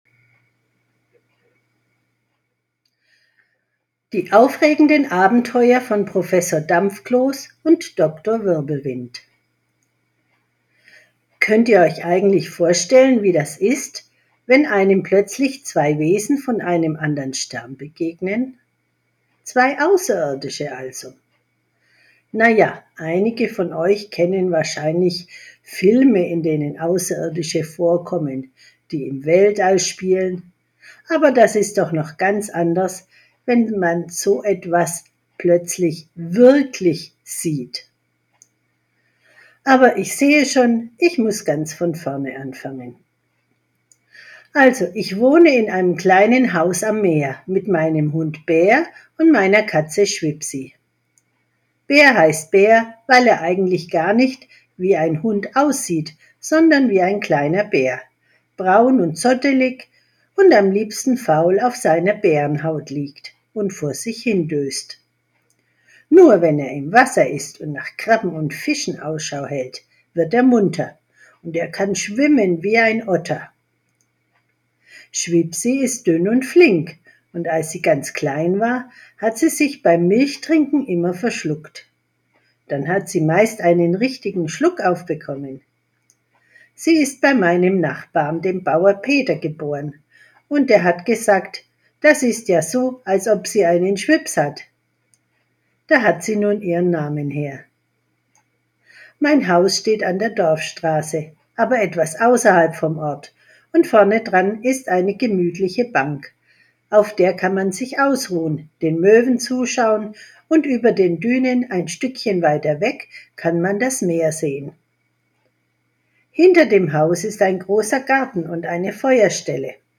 Leseprobe „Die aufregenden Abenteuer von Professor Dampfkloß und Doktor Wirbelwind“